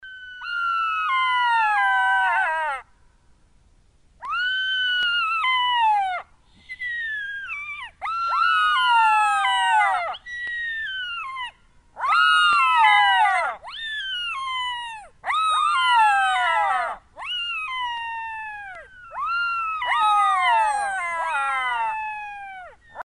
Шакал воем зовет сородичей